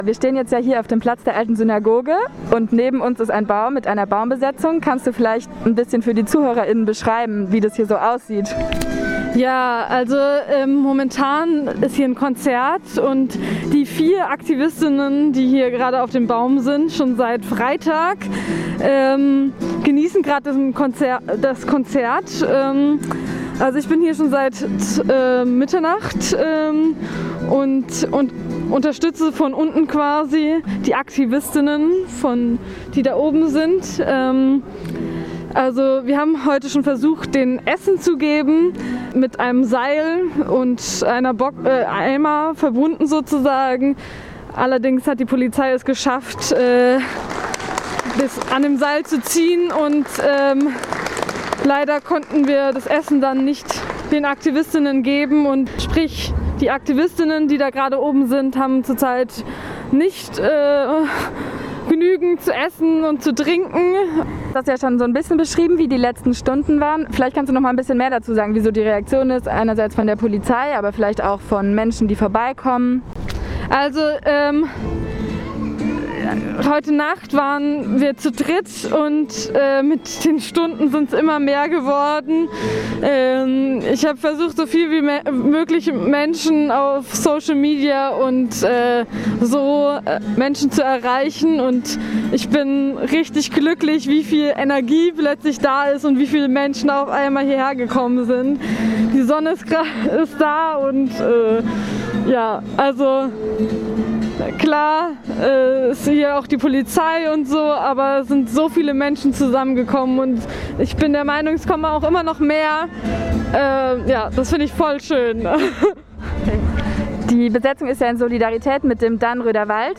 Wir haben mit einer anwesenden Person gesprochen.
Interview_bearbeitet.mp3